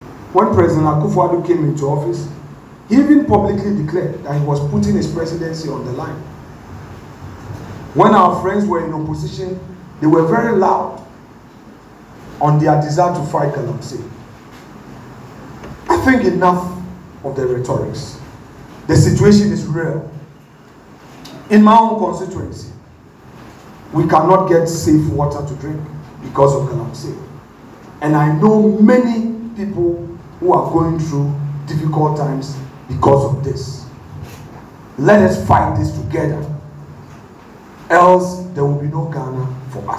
Speaking during an event to launch the Young Commons Forum at the University of Professional Studies, Accra (UPSA), Afenyo-Markin observes that the situation in Effutu constituency is particularly dire, despite the government’s claims of combating the menace.